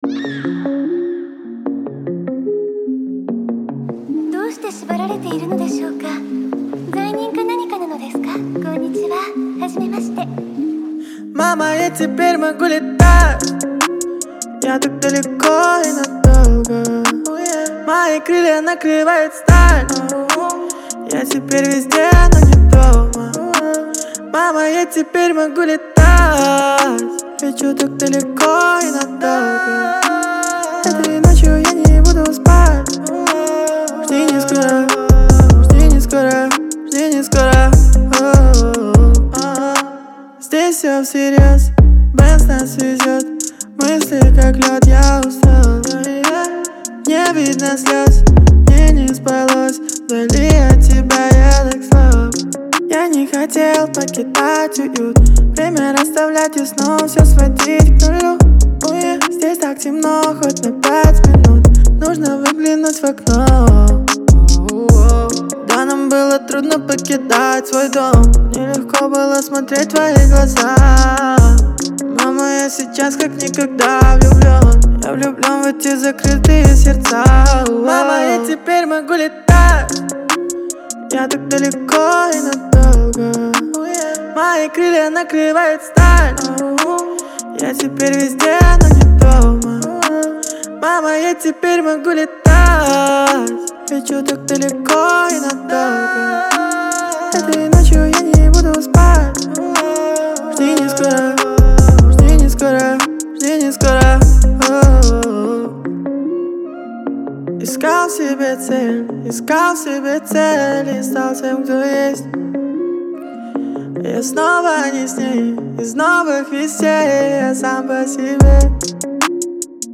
это атмосферный трек в жанре альтернативного рока